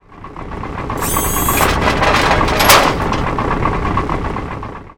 droneout.wav